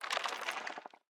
parrot_imitate_creaking.ogg